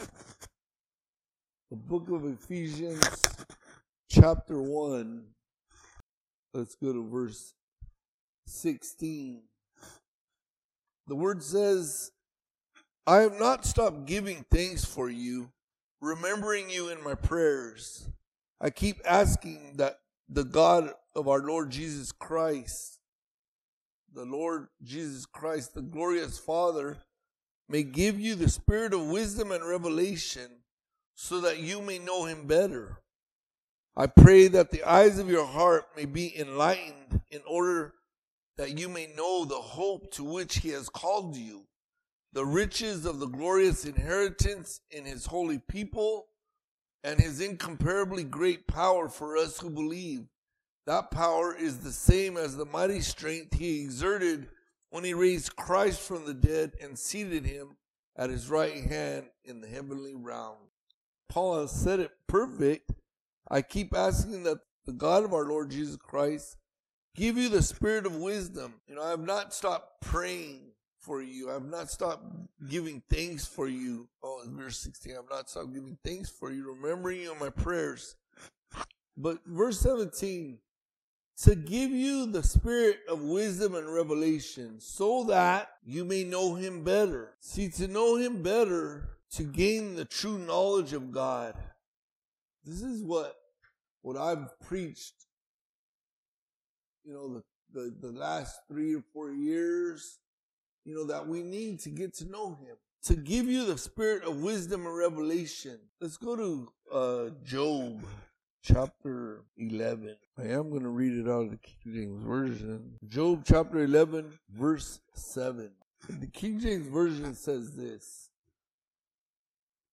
All Sermons Enlighten the eyes of your heart!